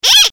clock10.ogg